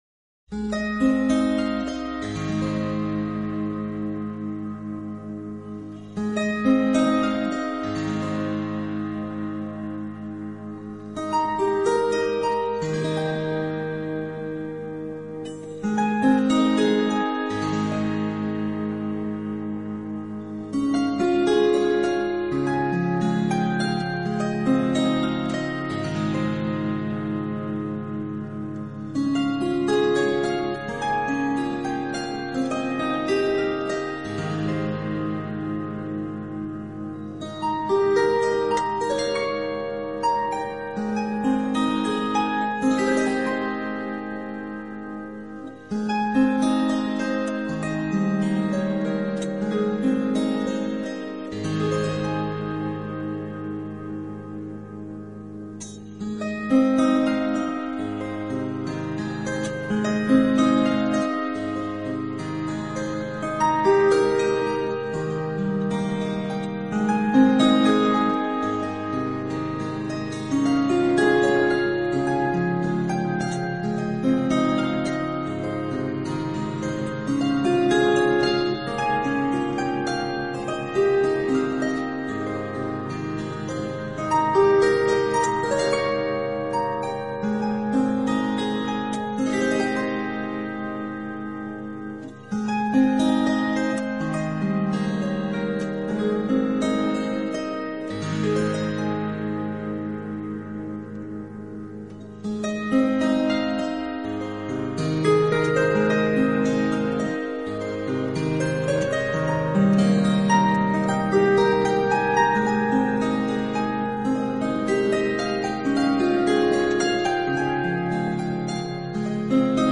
他的竖琴音乐象是水晶，声脆而美丽。